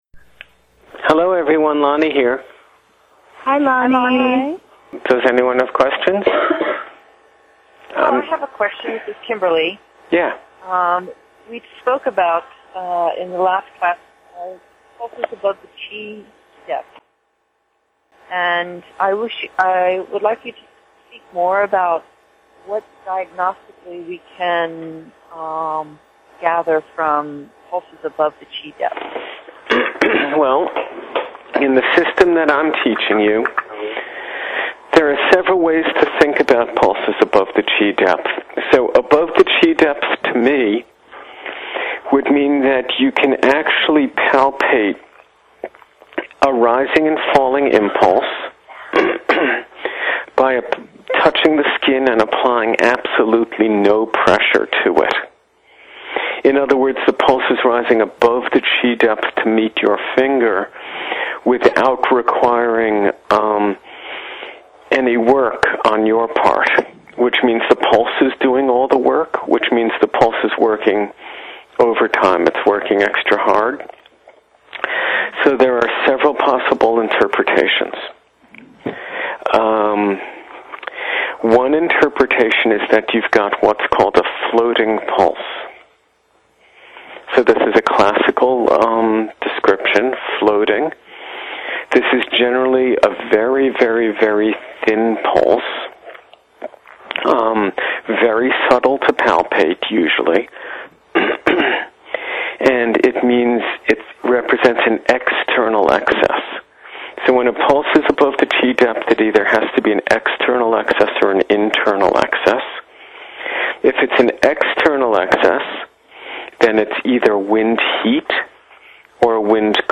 CI-XII Conference Call
Unfortunately, the battery ran out after 40 minutes!